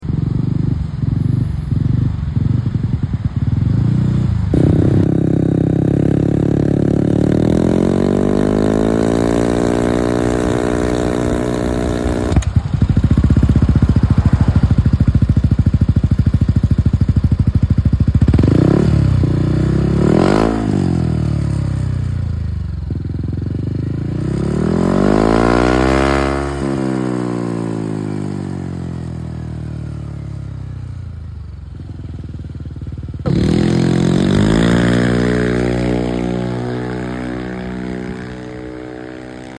Checksound XF 1 Exhaust For Sound Effects Free Download